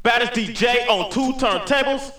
Badest dj on two turntables!.wav